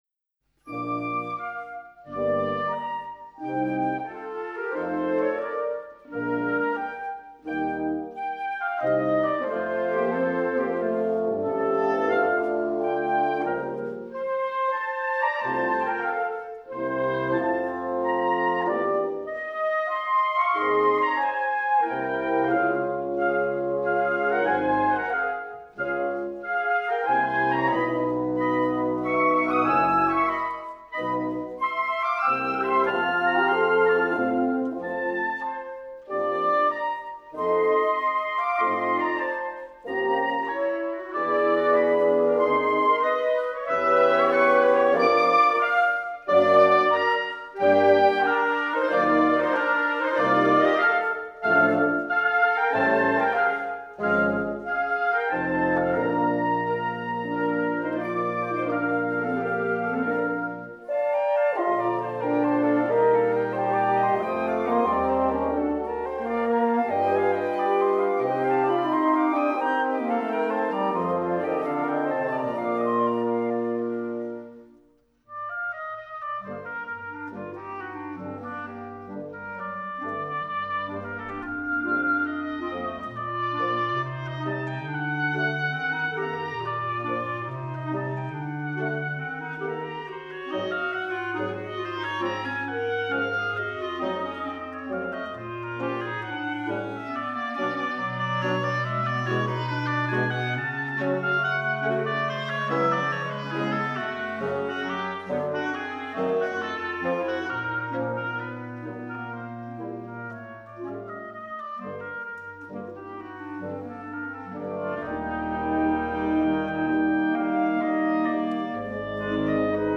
Symphonie für Bläser